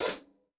Closed Hats
TC Live HiHat 09.wav